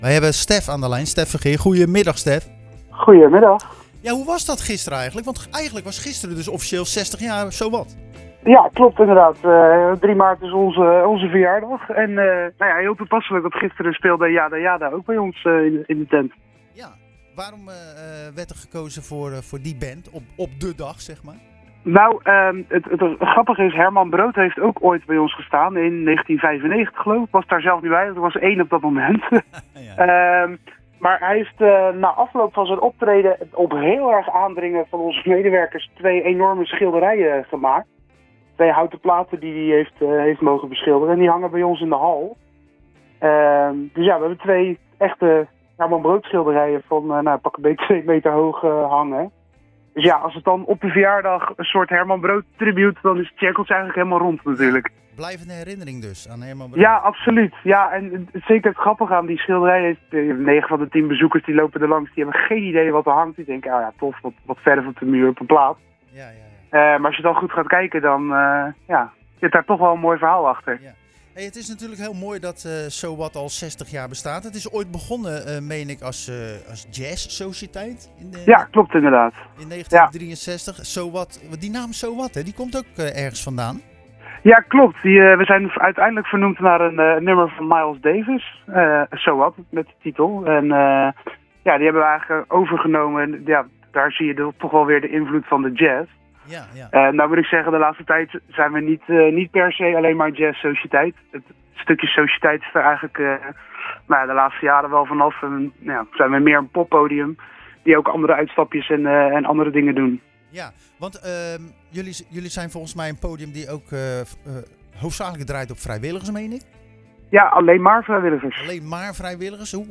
Tijdens de uitzending van Zwaardvis belde we met het jubilerende poppodium So What in Gouda. Op 3 maart bestonden zij precies 60 jaar.